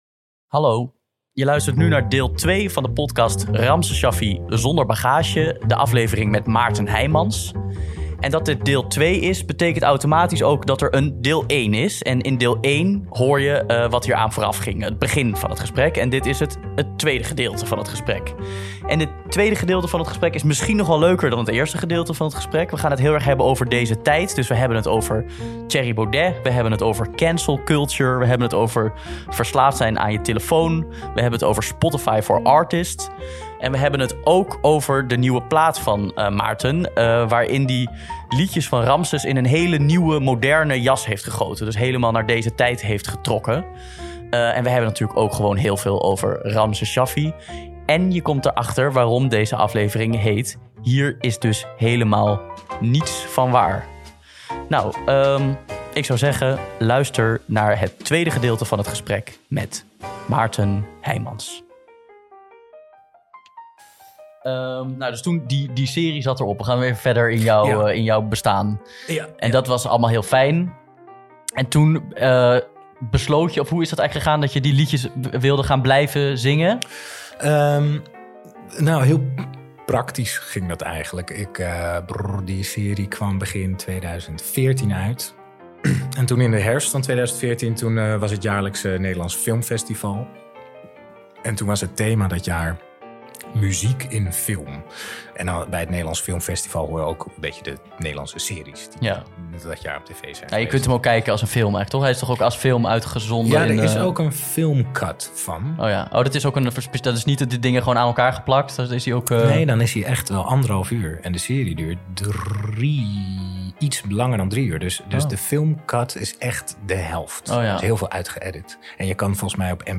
Deze aflevering spreek ik Maarten Heijmans.